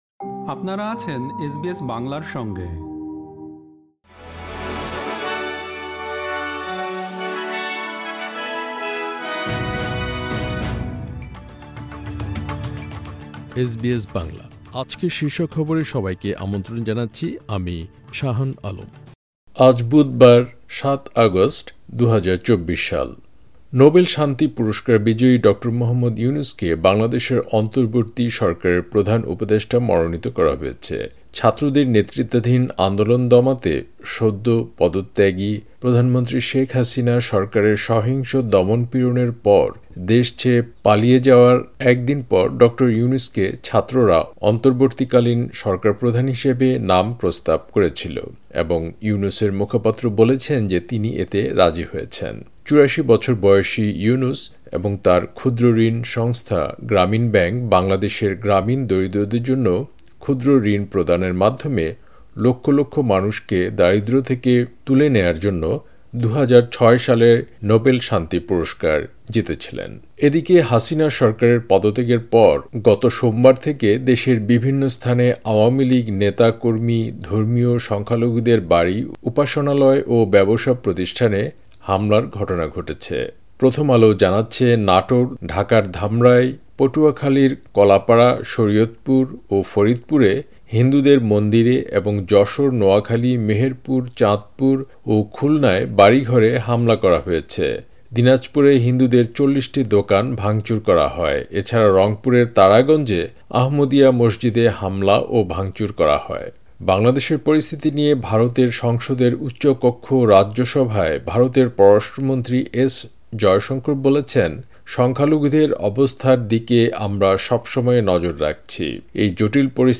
এসবিএস বাংলা শীর্ষ খবর: ৭ অগাস্ট, ২০২৪